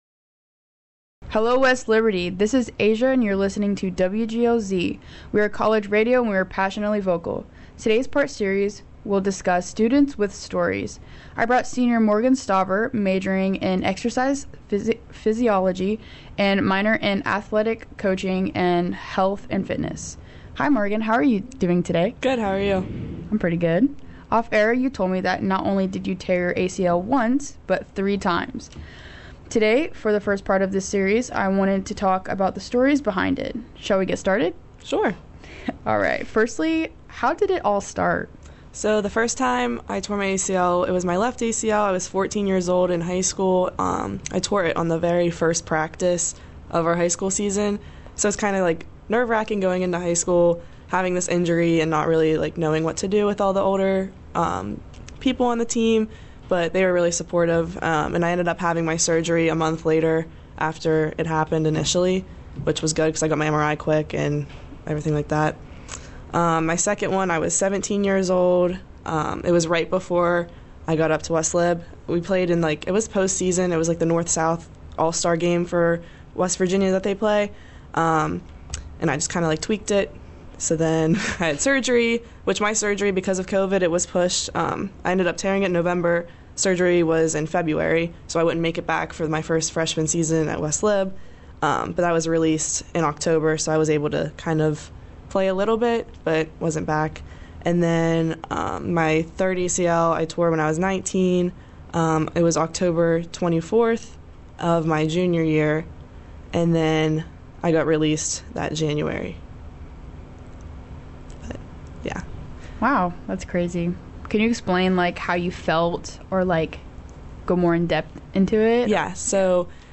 but tearing three isn’t. In this Interview